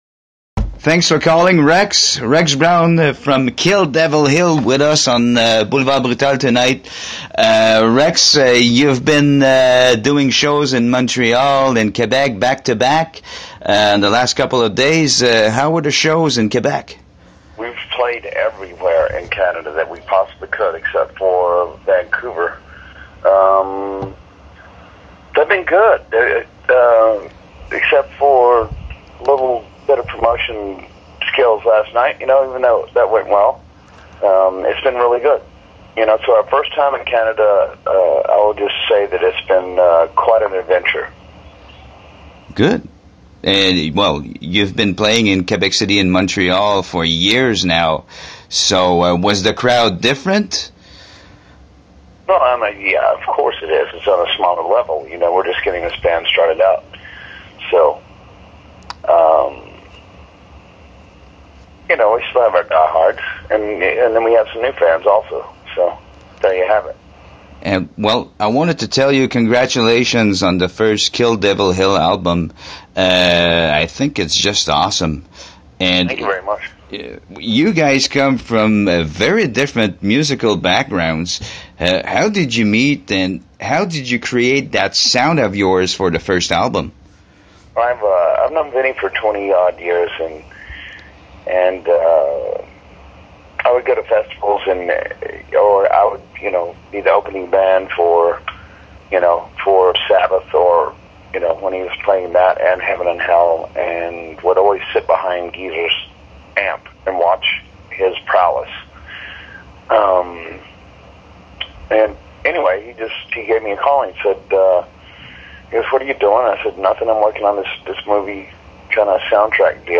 Rex Brown (Kill Devil Hill, Pantera) a pris le temps malgré la fatigue d’être en tournée avec son nouveau groupe Kill Devil Hill pour s’entretenir quelques minutes avec Boulevard Brutal. Entrevue où l’on discute de pleins de sujets mais ne cherchez pas de questions sur Pantera, le mot d’ordre étant de ne pas aborder le sujet.
entrevue-rex-brown1.mp3